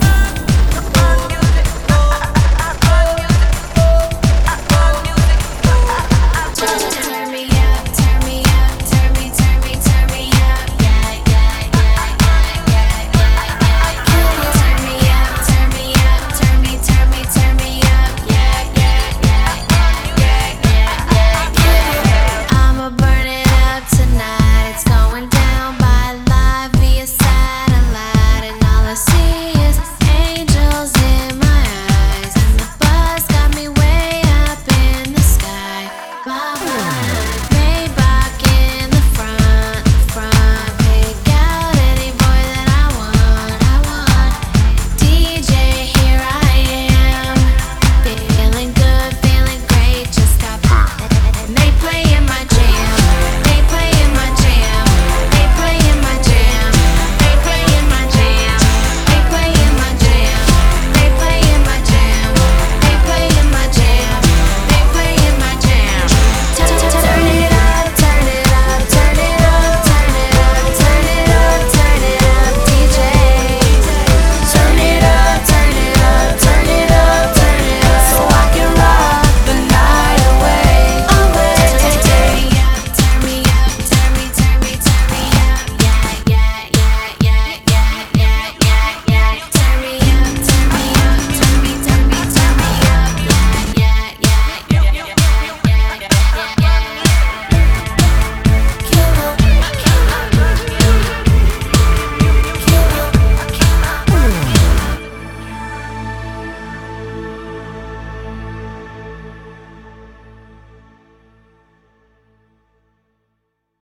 BPM128
Comments128 BPM